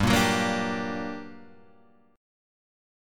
G Minor Major 7th